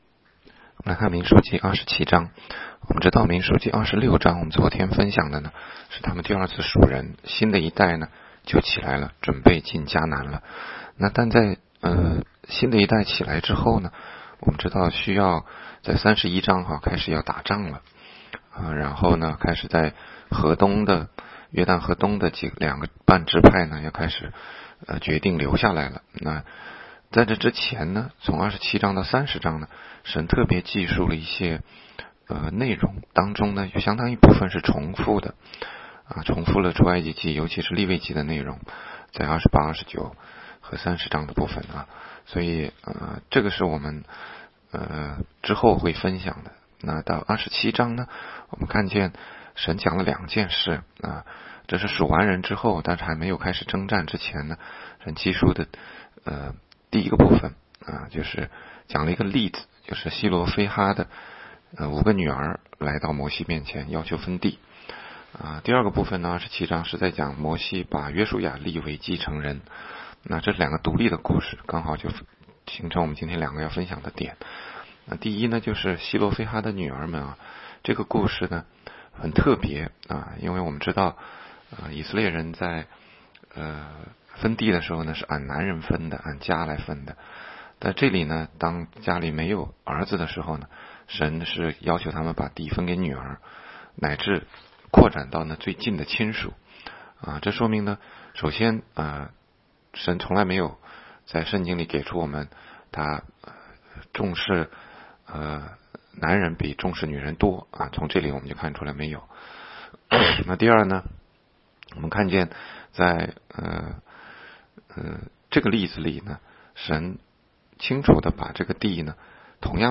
16街讲道录音 - 每日读经-《民数记》27章
每日读经
每日读经-民27章.mp3